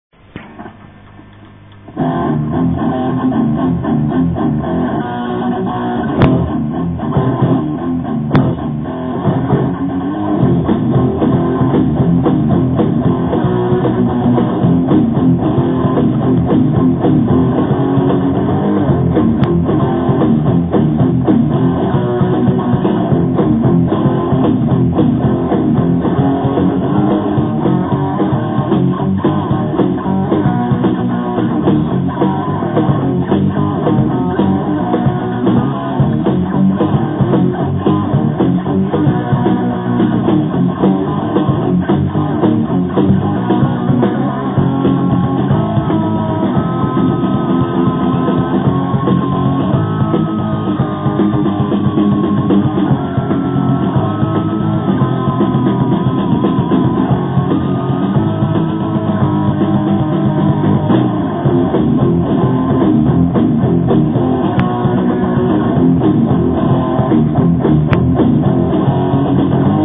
Guitars, Vocals
Drums, Vocals, Bass